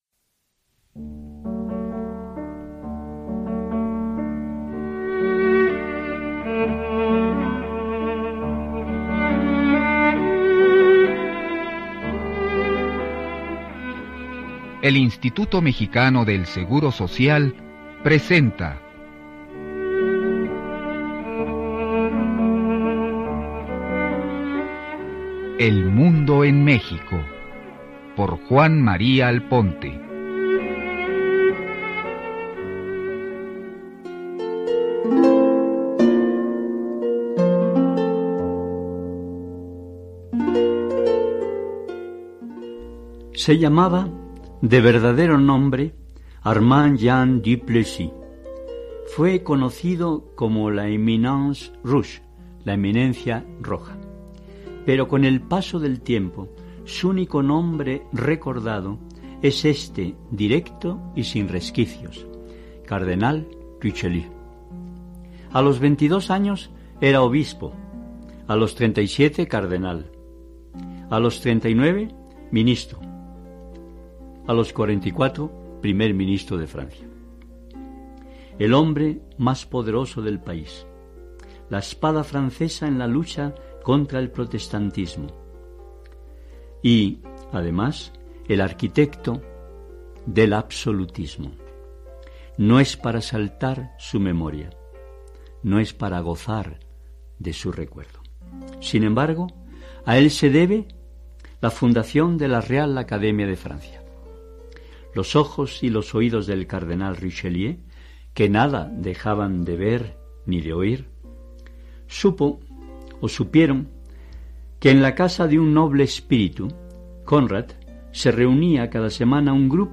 Escucha una semblanza de Richelieu en el programa de Juan María Alponte, “El mundo en México”, transmitido en 2001.